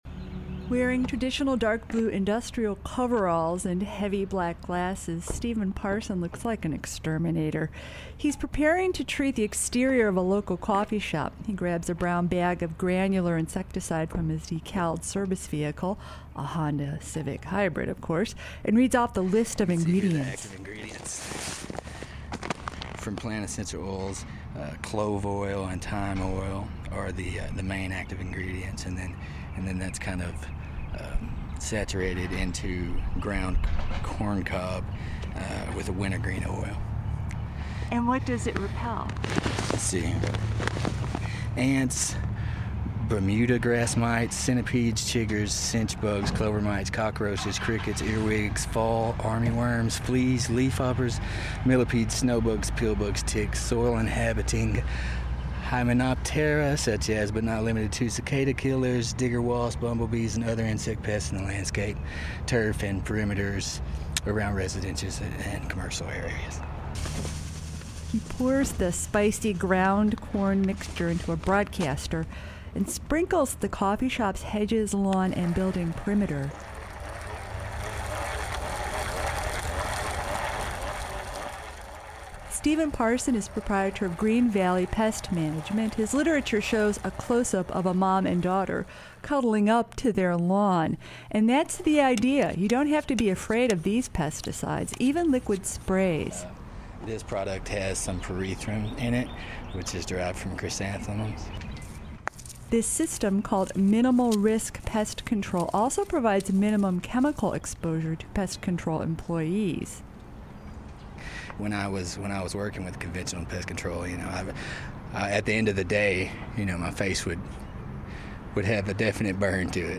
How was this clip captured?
goes out on a job with him and reports on an emerging industry